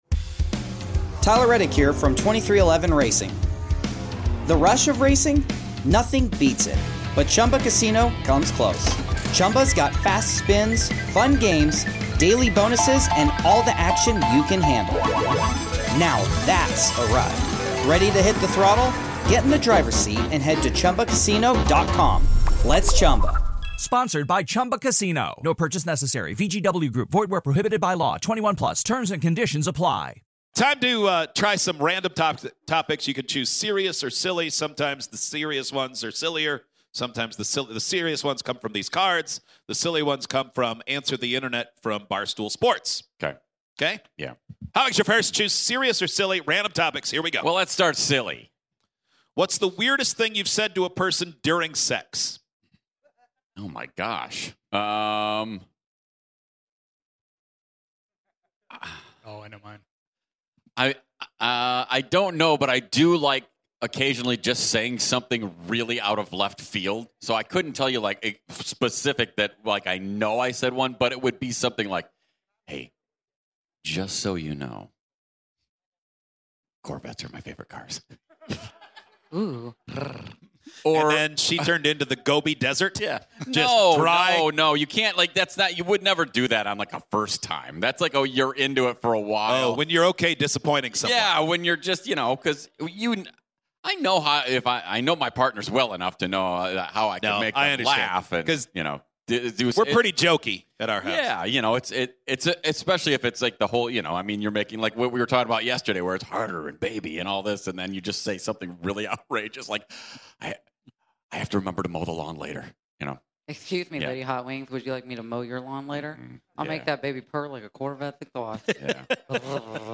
Random Questions - Live in Nashville!
Let's get to know the show through Random Questions in front of a live audience in Nashville, Tennessee.